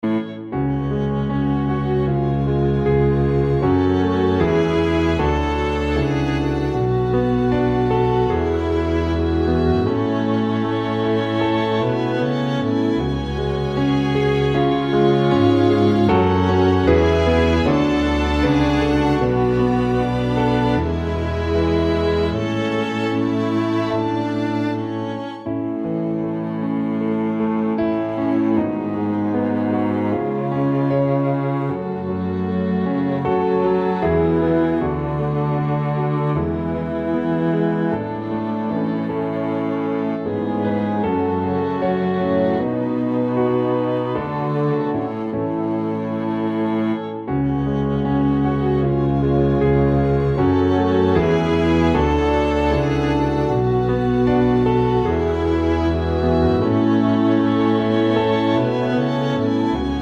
Up 4 Semitones for Female